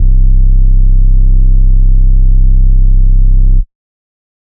goosebumps sub.wav